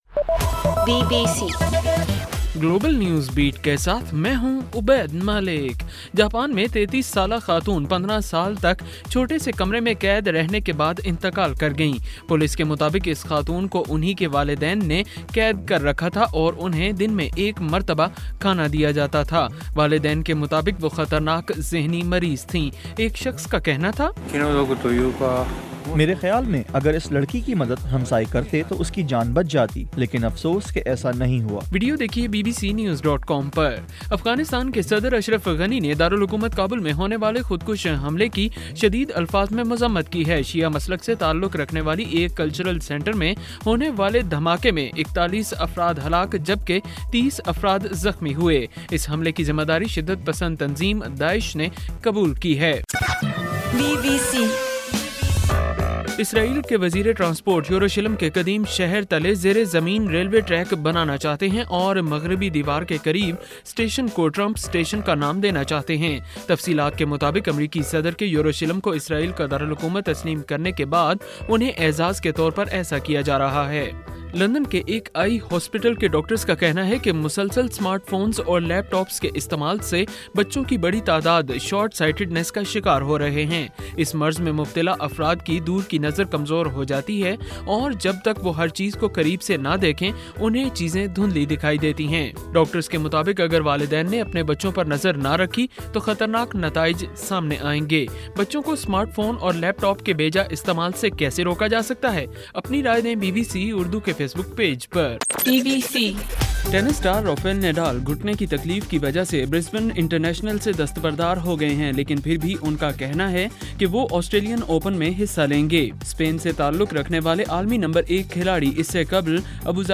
گلوبل نیوز بیٹ بُلیٹن اُردو زبان میں رات 8 بجے سے صبح 1 بجے ہرگھنٹےکے بعد اپنا اور آواز ایفایم ریڈیو سٹیشن کے علاوہ ٹوئٹر، فیس بُک اور آڈیو بوم پر